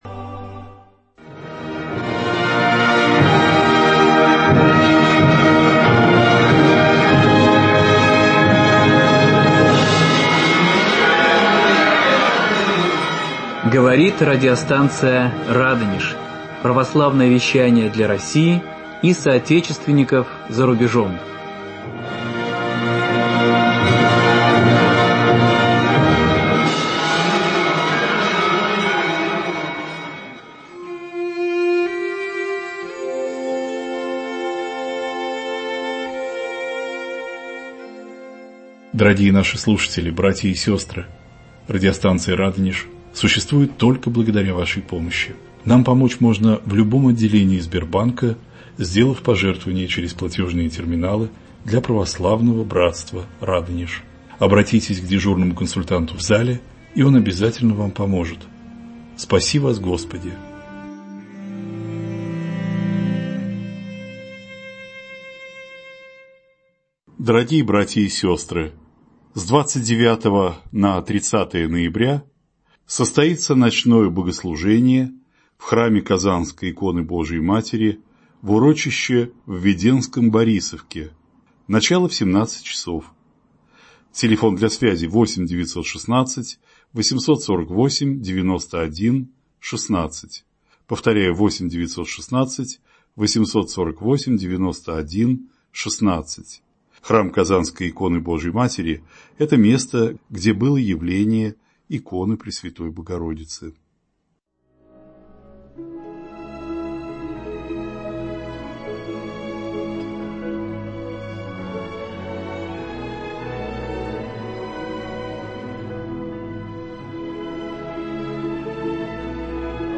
В студии радио